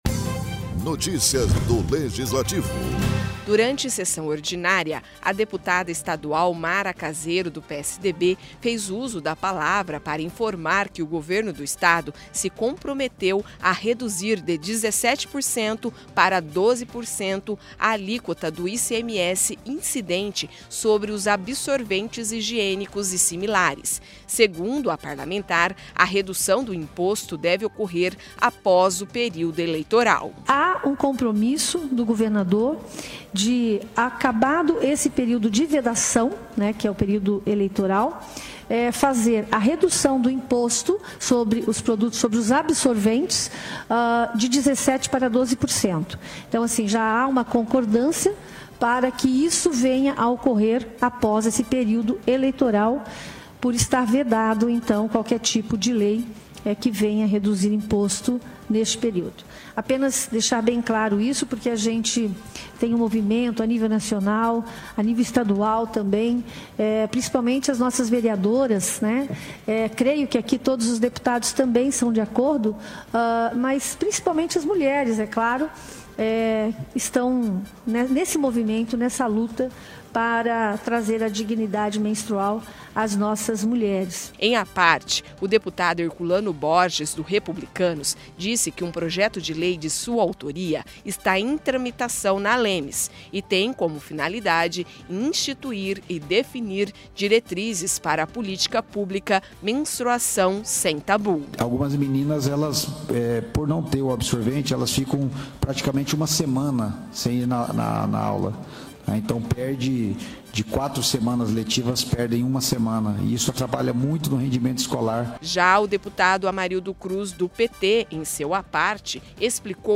Durante sessão ordinária, a deputada estadual Mara Caseiro (PSDB) fez uso da palavra para informar que o Governo do Estado se comprometeu a reduzir de 17% para 12% a alíquota do ICMS incidente sobre os absorventes higiênicos e similares. Segundo a parlamentar, a redução do imposto deve ocorrer após o período eleitoral.